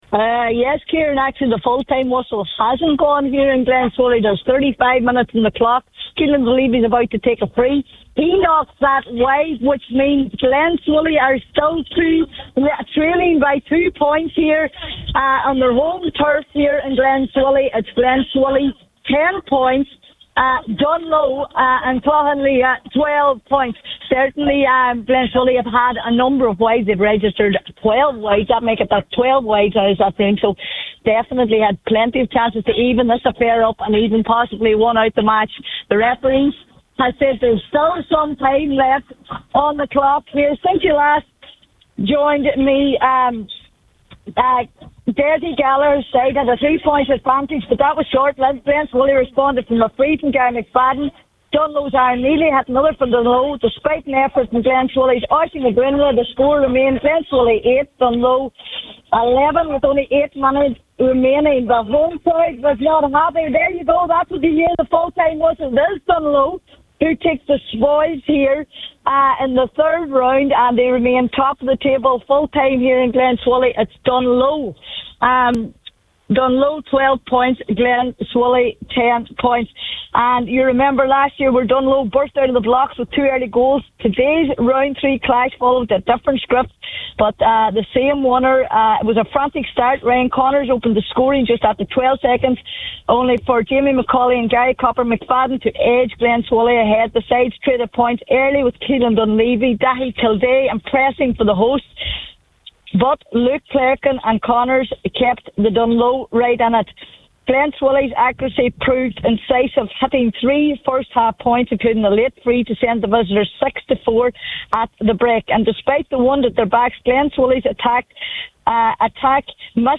full time report…